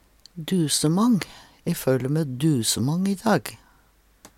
dusemang - Numedalsmål (en-US)